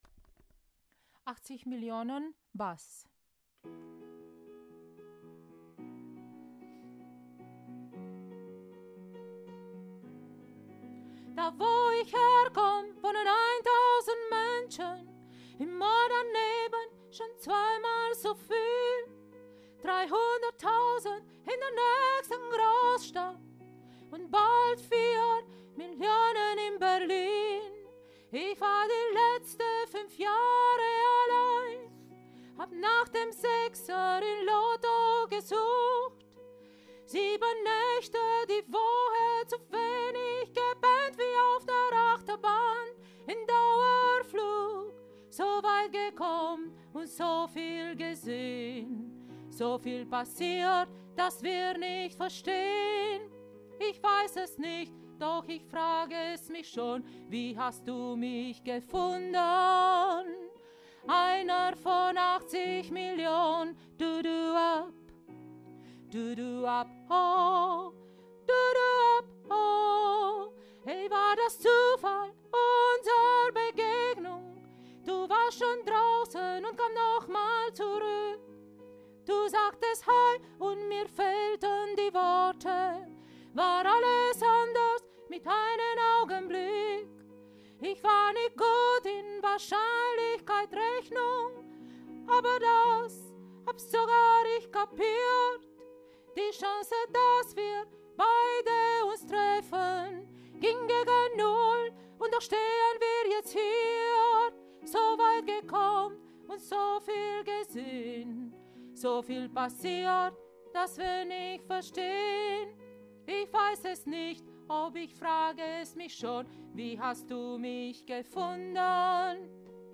80 Millionen – Bass